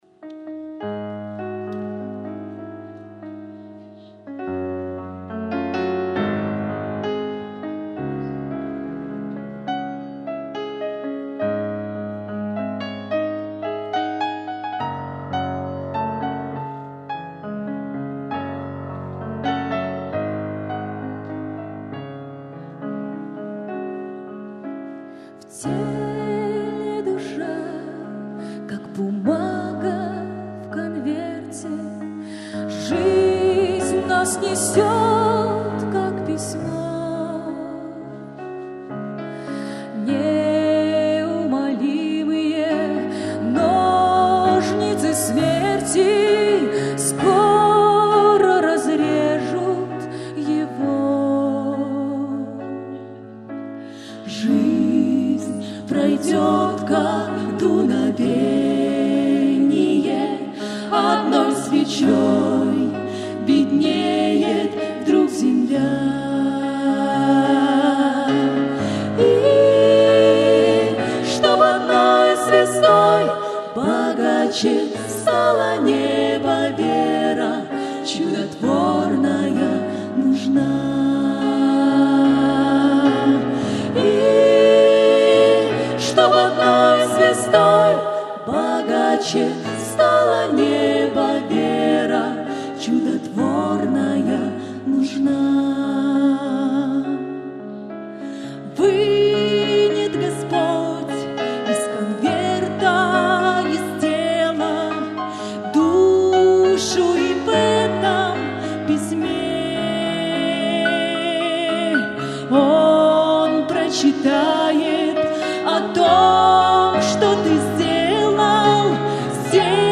Псалмы группы